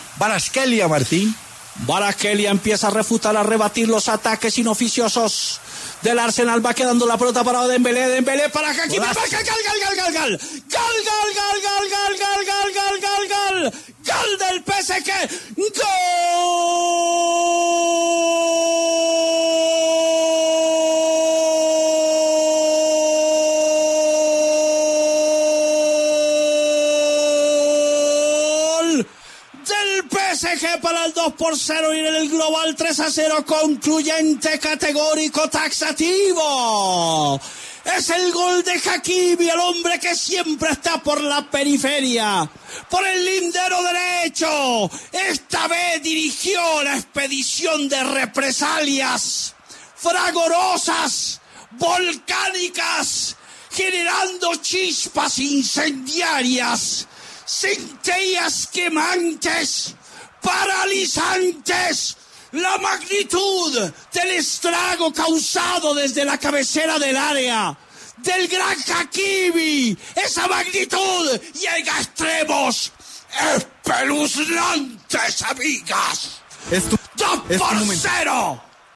“Concluyente, categórico y taxativo”: Así narró el gol del PSG Martín de Francisco